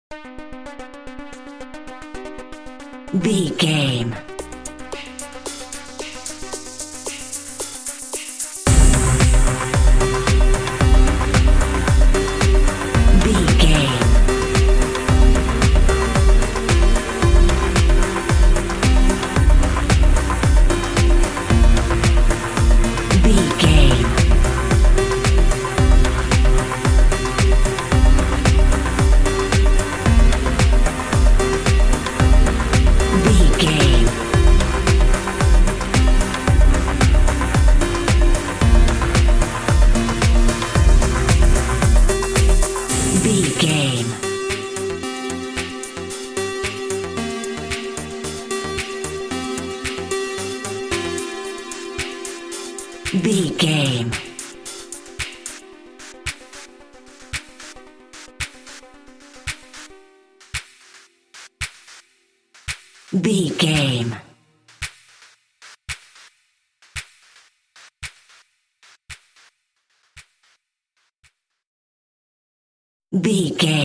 Aeolian/Minor
groovy
uplifting
driving
energetic
repetitive
synthesiser
drum machine
house
techno
instrumentals
synth leads
synth bass
upbeat